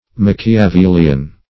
Machiavelian \Mach`i*a*vel"ian\, Machiavellian